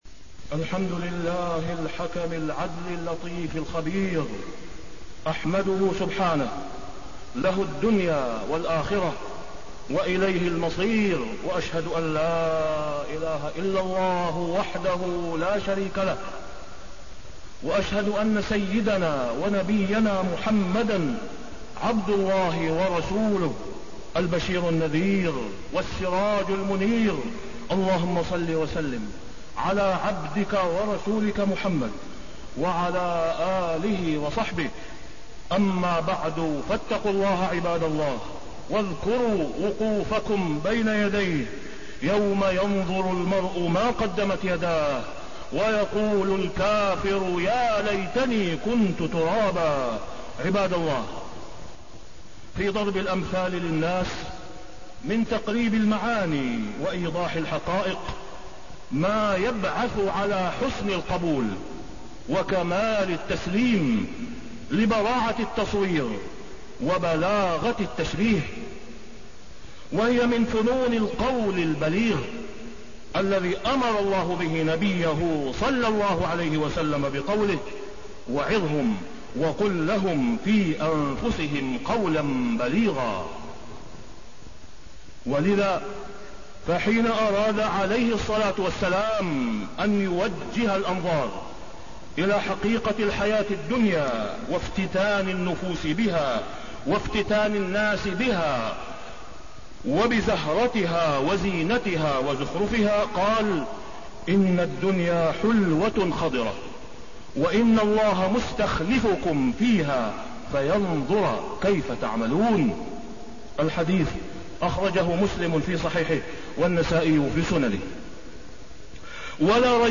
تاريخ النشر ٣ صفر ١٤٣٢ هـ المكان: المسجد الحرام الشيخ: فضيلة الشيخ د. أسامة بن عبدالله خياط فضيلة الشيخ د. أسامة بن عبدالله خياط الفرق بين نعيم الدنيا ونعيم الآخرة